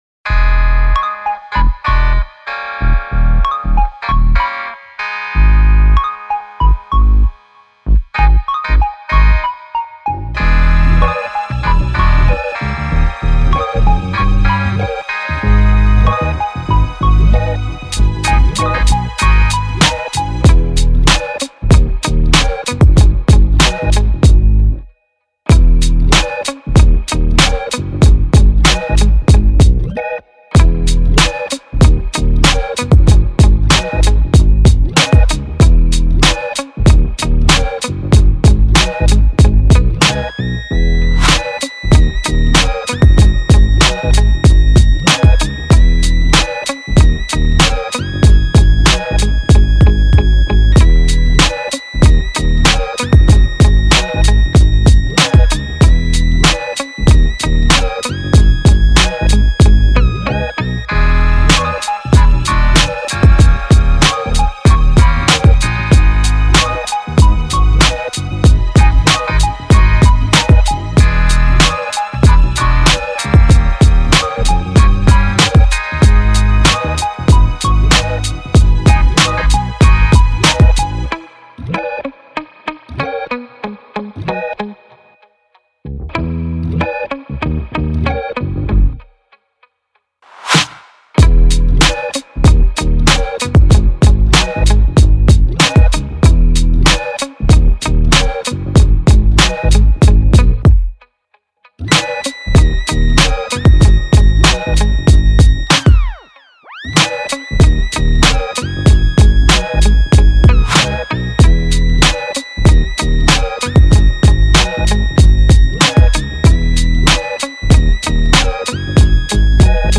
official instrumental
Rap Instrumentals